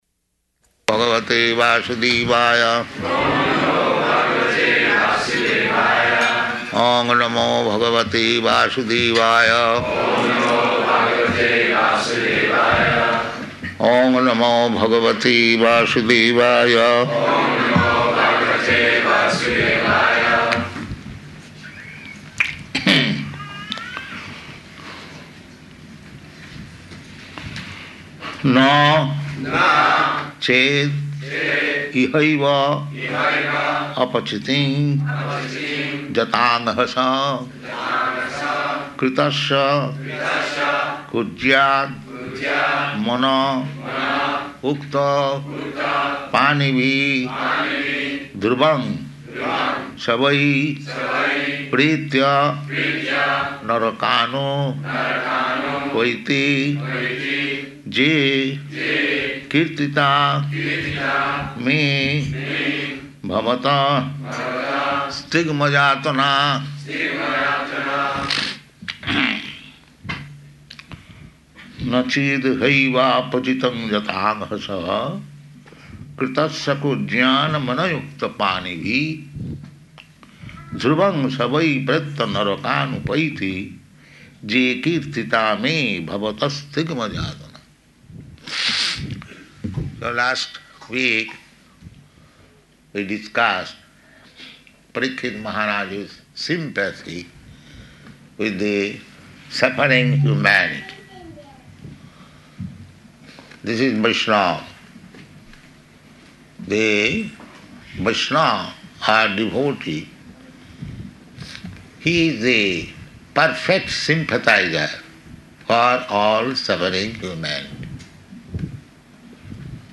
Śrīmad-Bhāgavatam 6.1.7 --:-- --:-- Type: Srimad-Bhagavatam Dated: June 15th 1975 Location: Honolulu Audio file: 750615SB.HON.mp3 Prabhupāda: Oṁ namo bhagavate vāsudevāya.
[chants with devotees responding] [chants verse word for word with devotees responding]